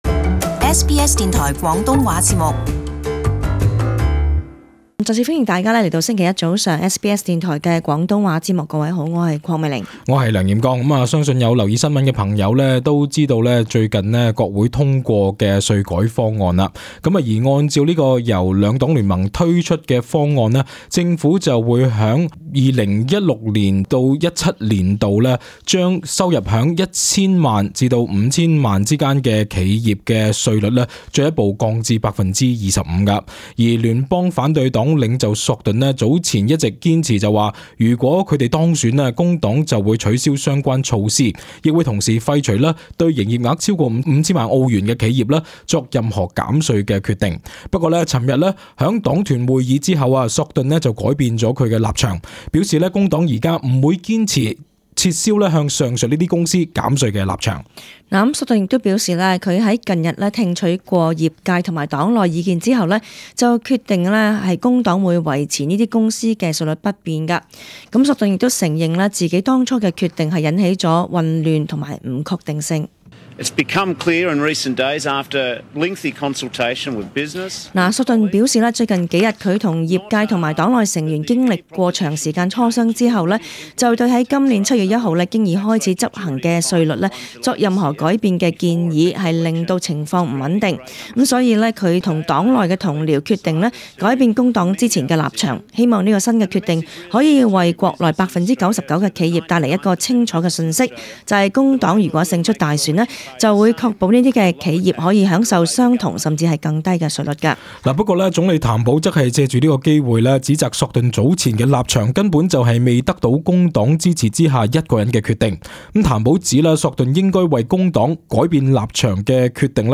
【時事報導】索頓：工黨不再堅持撤銷中小企稅務優惠